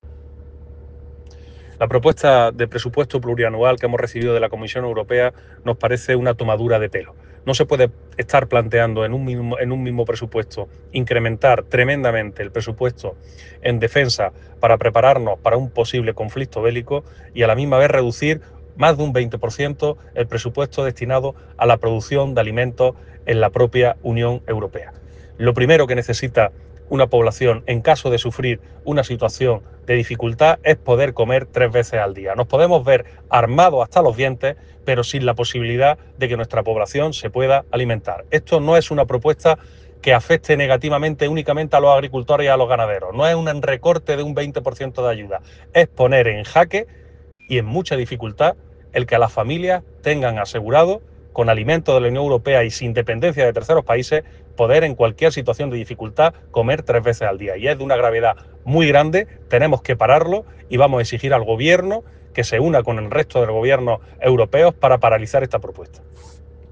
AUDIO CON DECLARACIONES